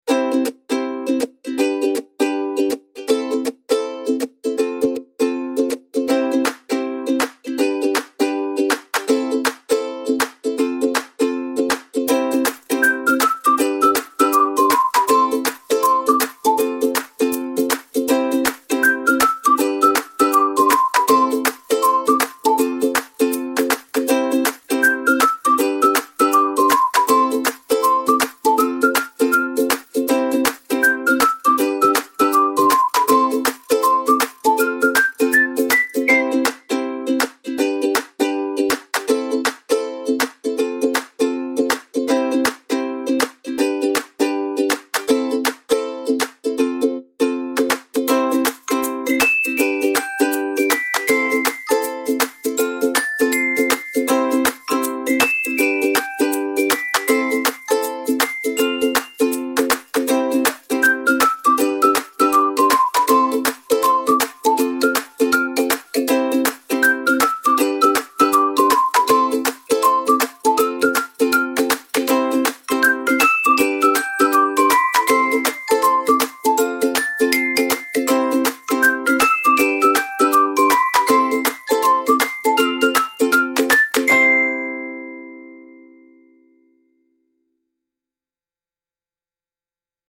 kazoo and ukulele comedy jam with claps and whistling